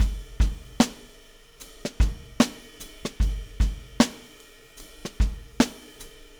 Weathered Beat 07.wav